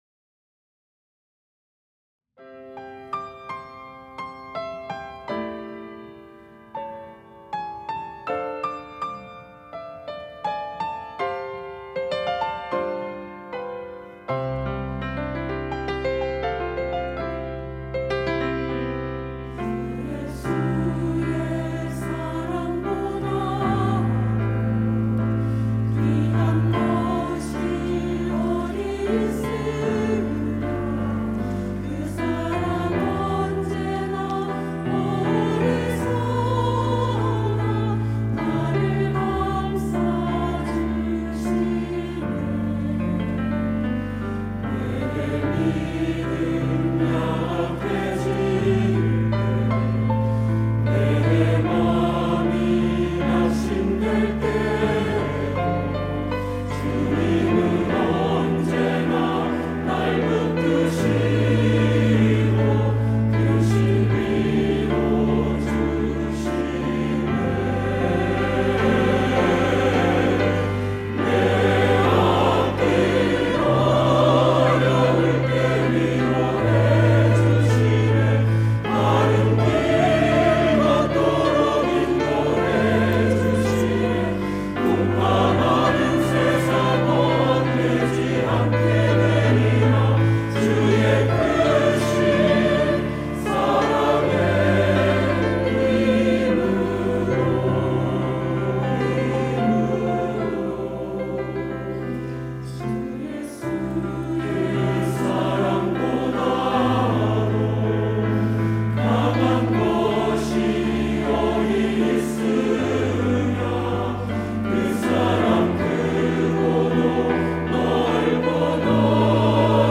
할렐루야(주일2부) - 주의 크신 사랑의 힘으로
찬양대